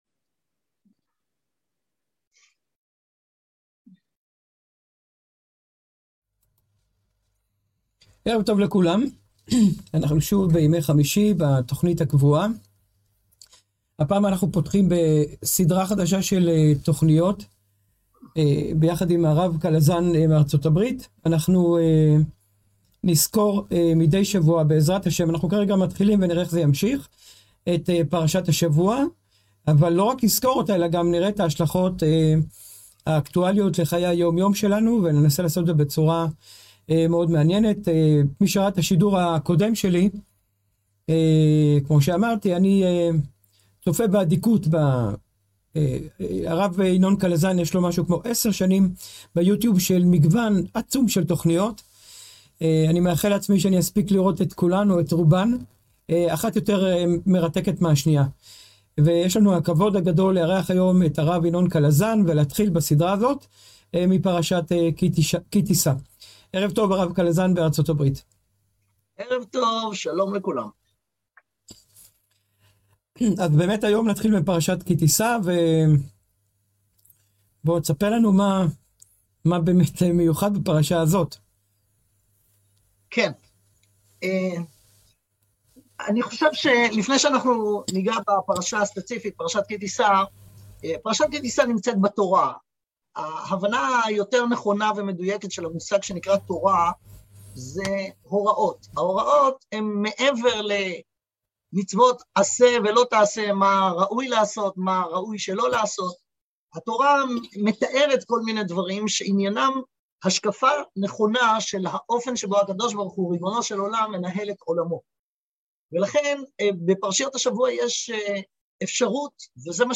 שיחות על פרשת השבוע והשלכות אקטואליות